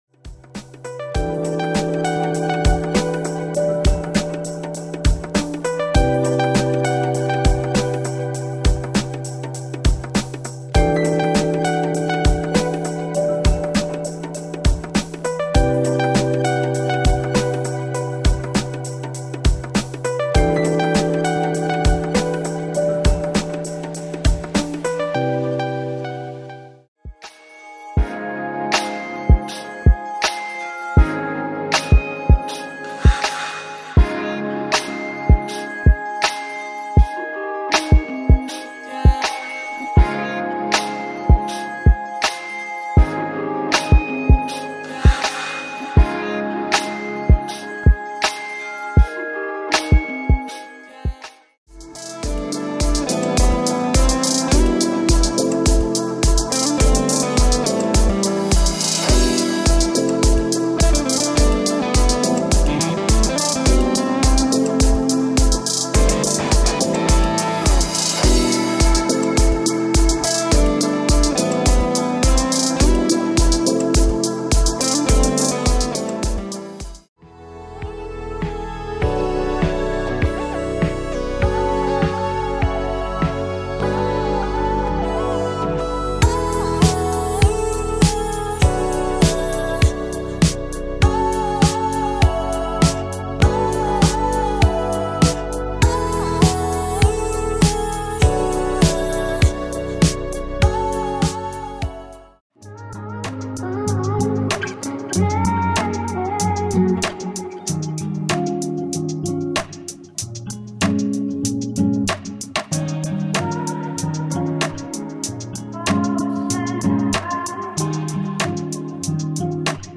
styl - jazz/smooth/lounge